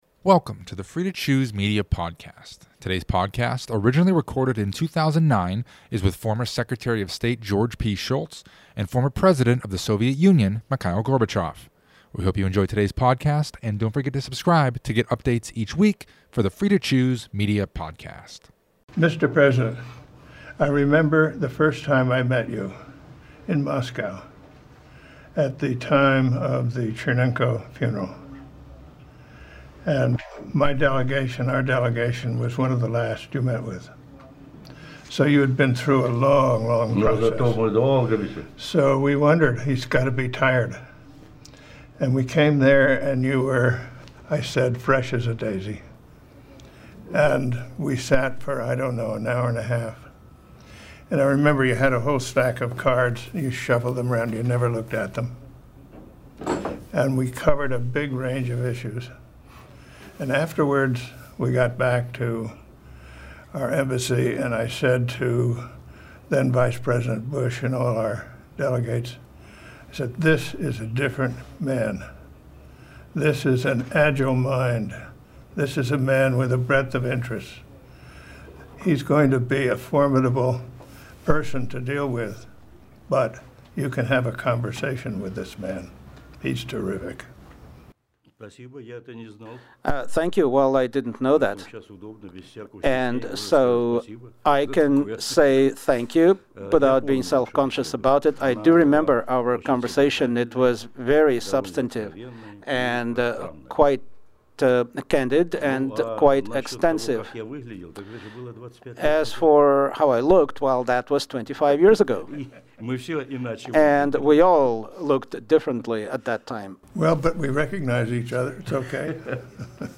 George Shultz, former Secretary of State, and Mikhail Gorbachev, former President of the Soviet Union, were responsible for the initial meetings that led to melting the ice of the Cold War. In this conversation, the two diplomats talk about their first meeting and the impressions each had on the other.